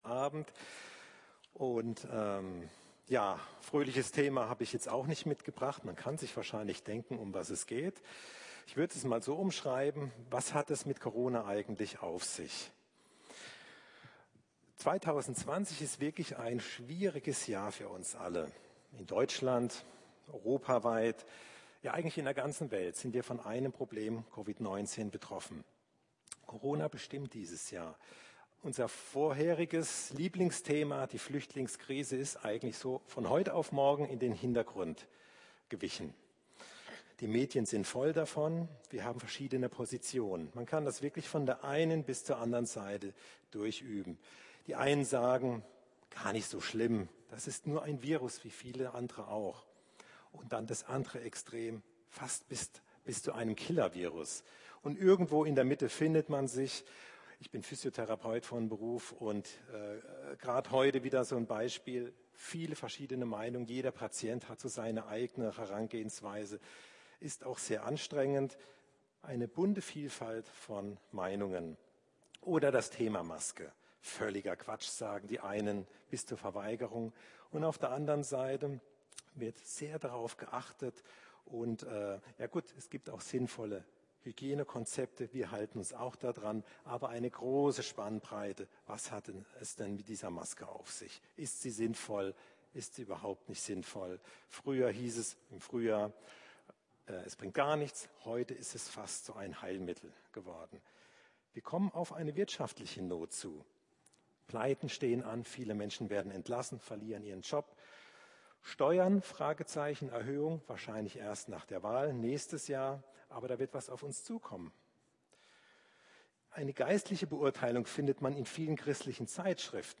Abendmahl , Predigt